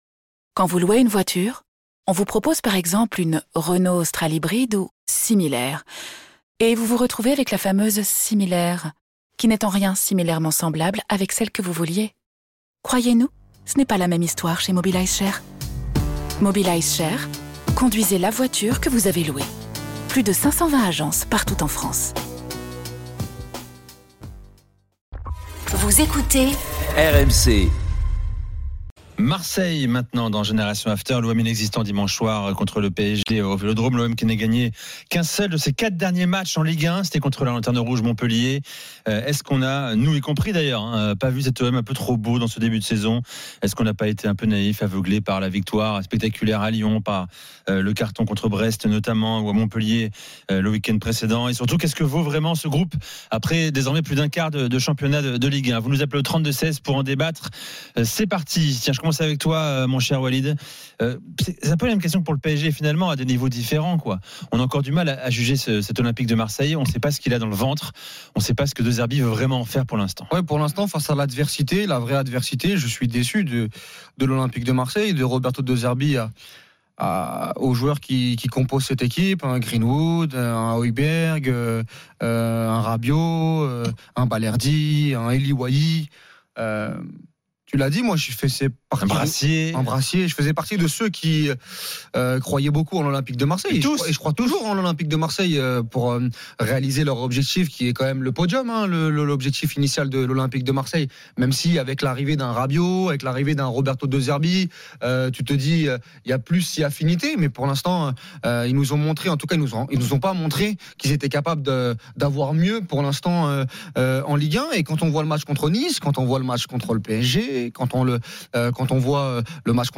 Au programme, des débats passionnés entre experts et auditeurs RMC, ainsi que de nombreux invités.